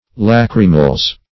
Search Result for " lachrymals" : The Collaborative International Dictionary of English v.0.48: Lachrymals \Lach"ry*mals\, n. pl.
lachrymals.mp3